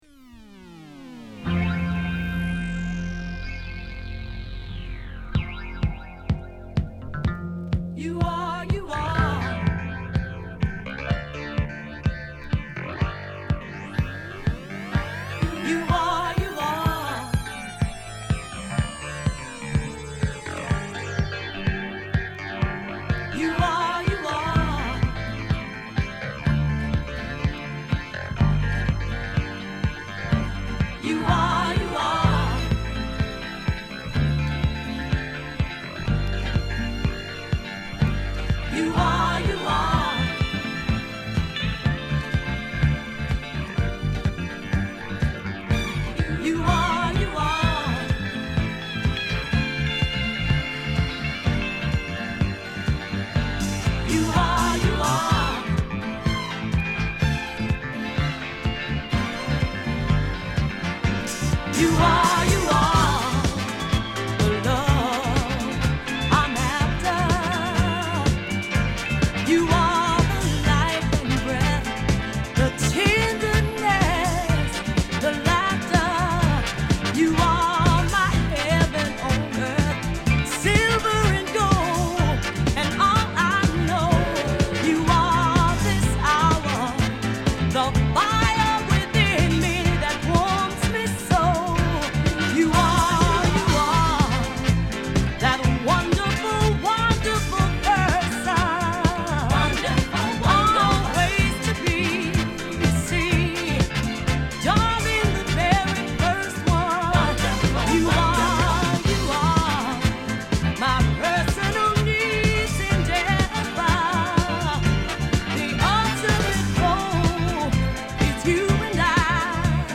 ダンストラックが充実したアルバムです！
※試聴はA2→A3です。